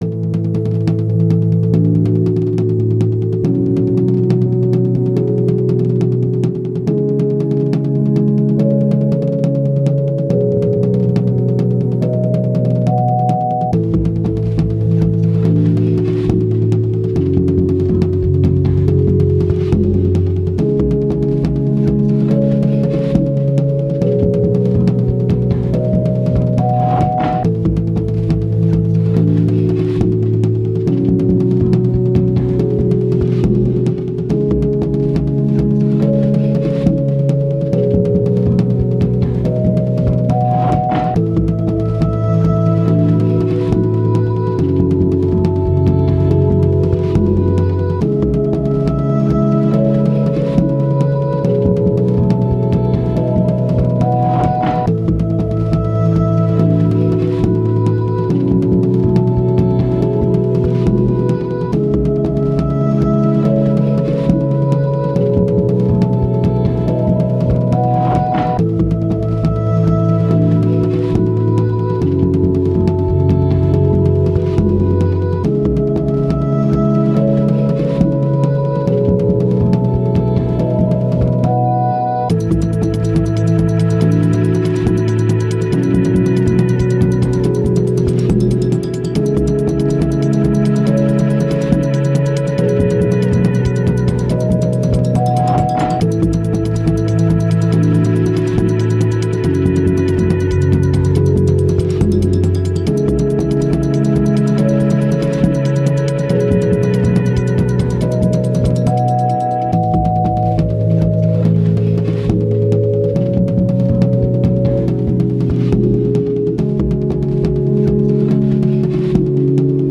Ambient, Downtempo, Electronic, Thoughtful, Space, Night